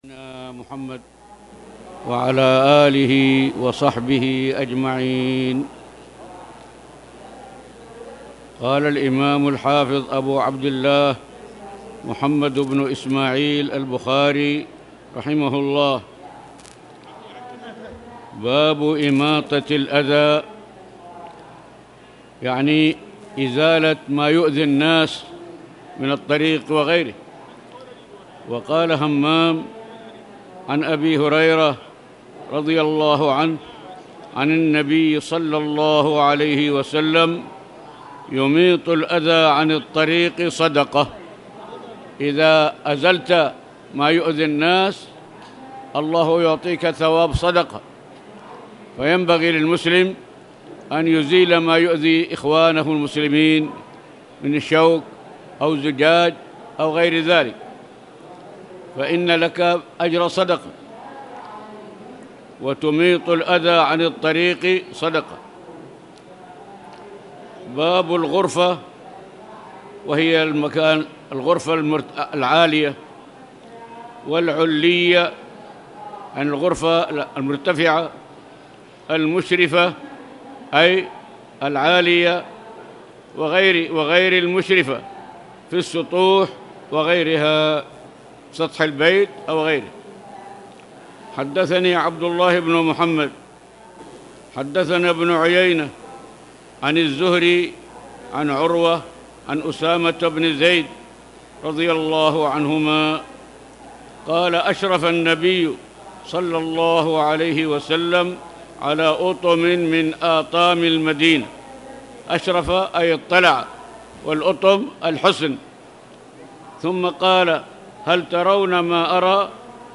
تاريخ النشر ٨ رجب ١٤٣٨ هـ المكان: المسجد الحرام الشيخ